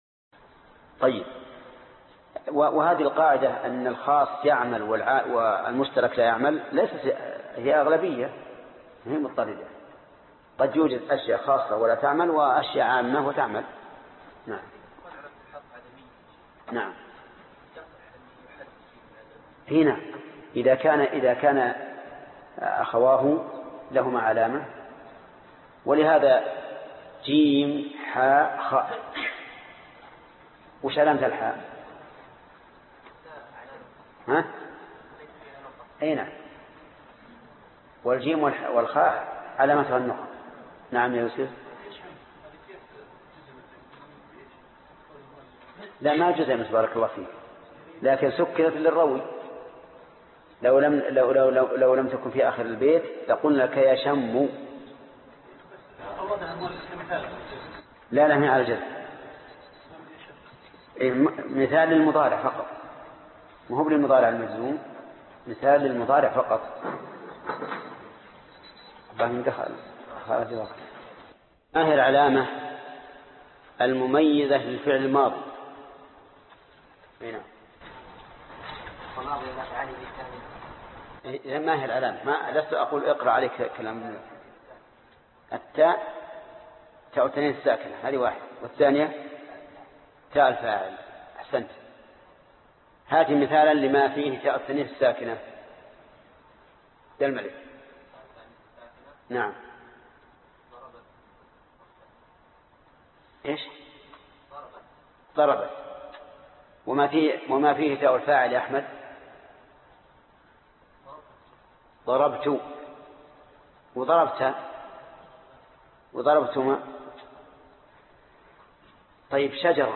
الدرس 9 المعرب والمبنى1 - الأبيات 15و16 (شرح الفية ابن مالك) - فضيلة الشيخ محمد بن صالح العثيمين رحمه الله